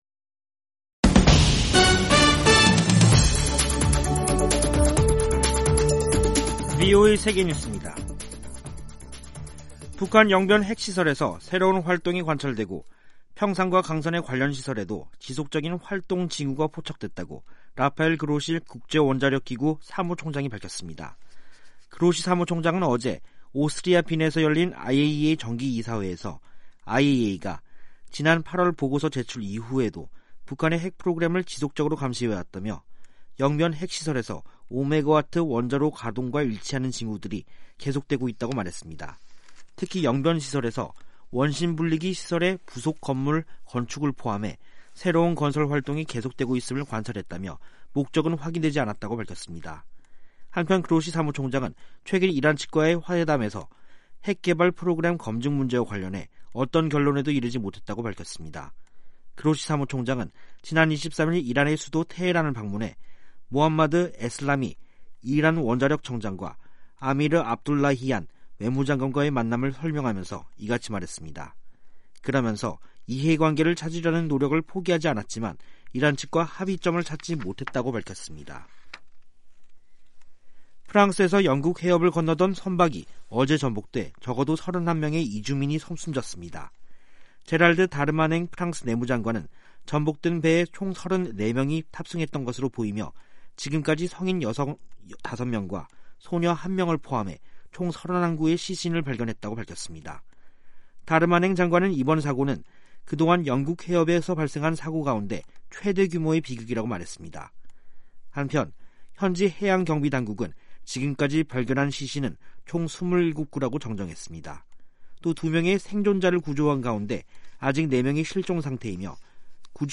세계 뉴스와 함께 미국의 모든 것을 소개하는 '생방송 여기는 워싱턴입니다', 2021년 11월 25일 저녁 방송입니다. '지구촌 오늘'에서는 독일 3개 정당이 총선 두 달 만에 연립정부 구성에 합의한 가운데 올라프 숄츠 사민당 후보는 독일의 새 총리가 될 전망이란 소식, '아메리카 나우'에서는 지난해 미국에서 조깅하던 흑인을 총으로 쏴 죽인 백인 남성 3명이 모두 유죄 평결을 받은 소식 전해드립니다.